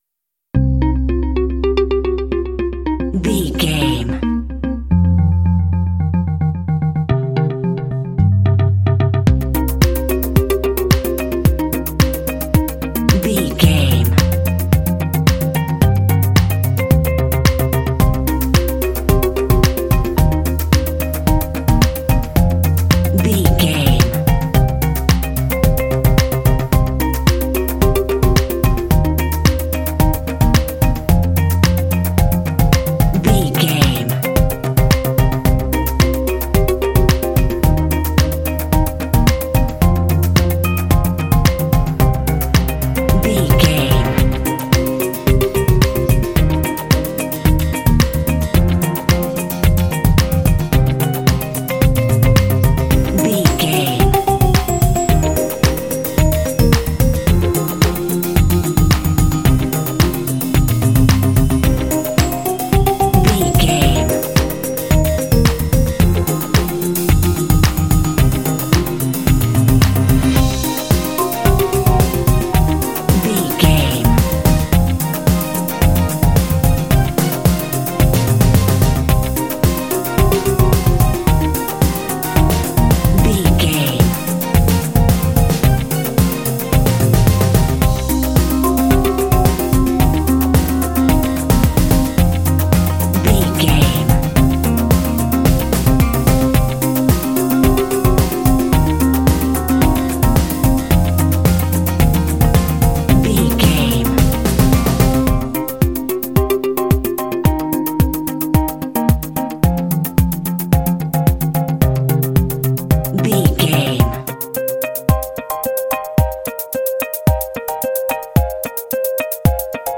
This uplifting synth-pop track will energize your game.
Aeolian/Minor
funky
groovy
synthesiser
drums
electric piano
electric guitar
bass guitar
strings
synth-pop